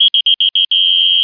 r_whist2.mp3